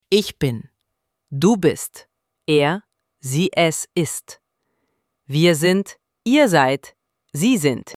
ElevenLabs_Text_to_Speech_audio-34.mp3